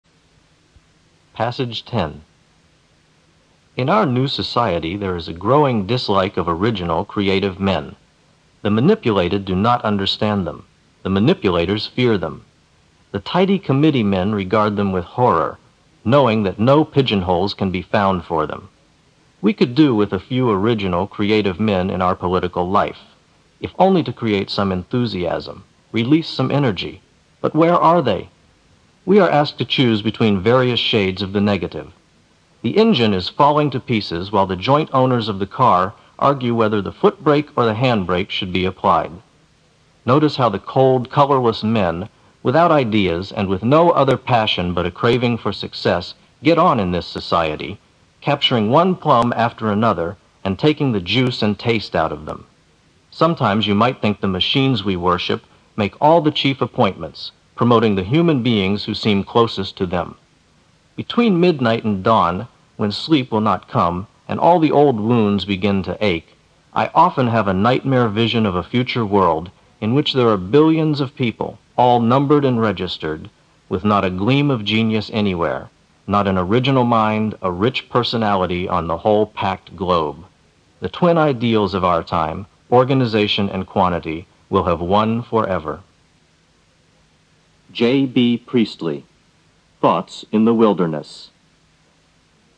新概念英语85年上外美音版第四册 第10课 听力文件下载—在线英语听力室